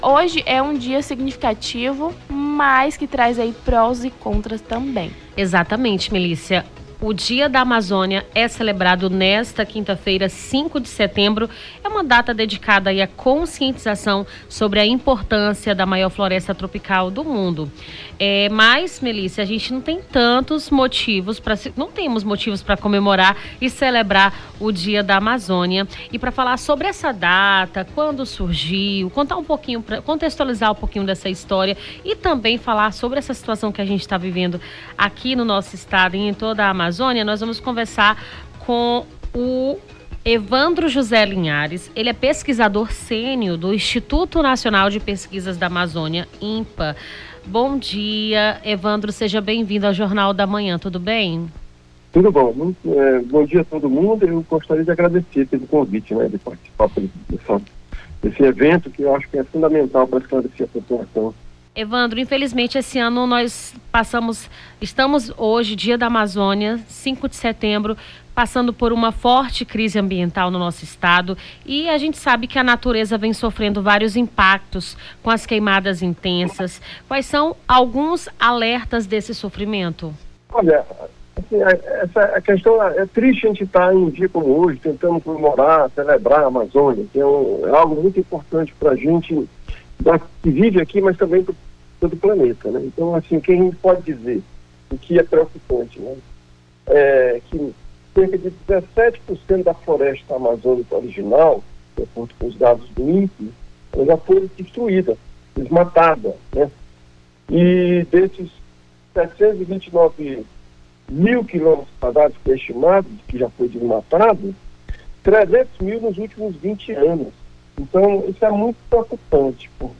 Nome do Artista - CENSURA - ENTREVISTA DIA DA AMAZÔNIA (05-09-24).mp3